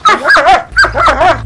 bark.wav